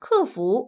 ivr-customer_service.wav